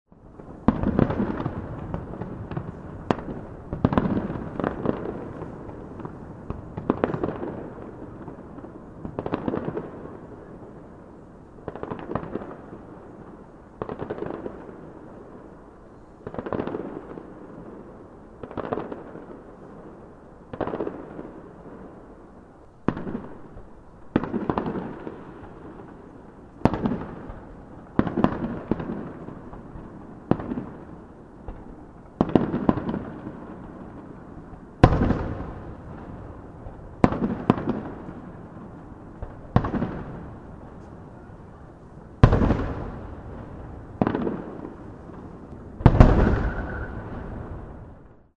День города Одинцово: фейерверк
Отметим, что завершилось празднование 52-го Дня рождения города Одинцово, по традиции, фейерверком.
Запись праздничных залпов можно
radio_feyer.mp3